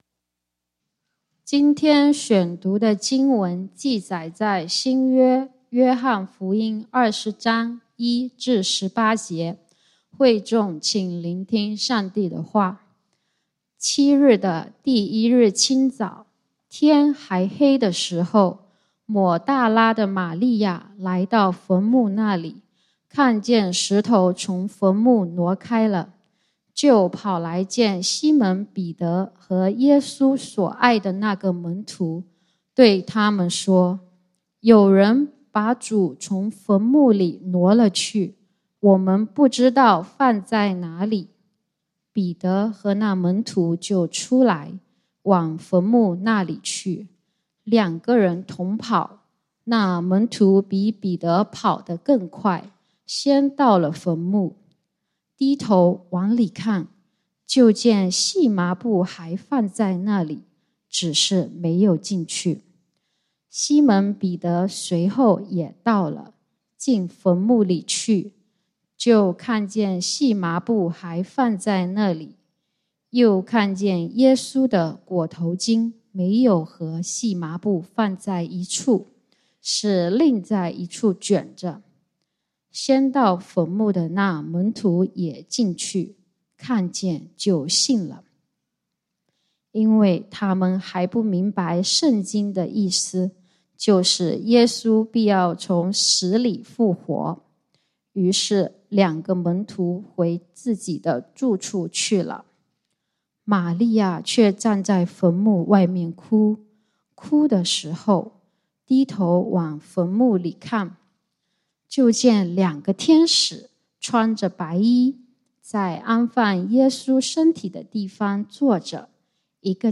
復活節崇拜：你為什麼哭？(經文：約翰福音 20:1-18 ) | External Website | External Website